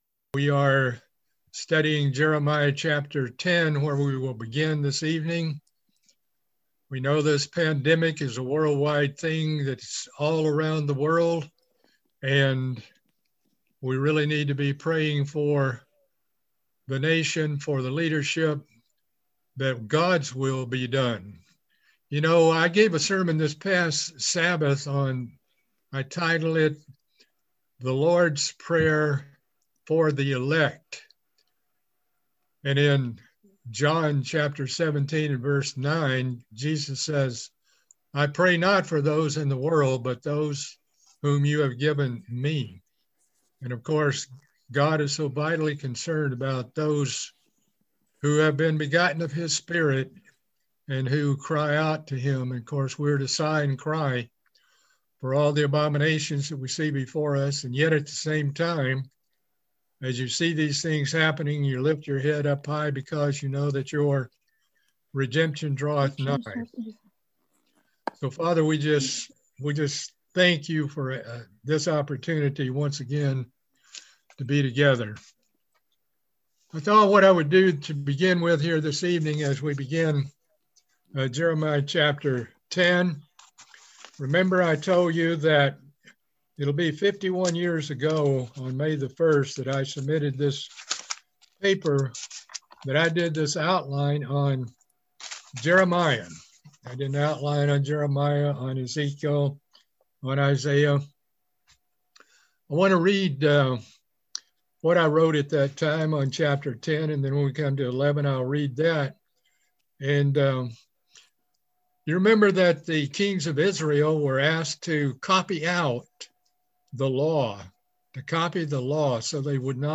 Part 7 of a Bible Study series on the book of Jeremiah.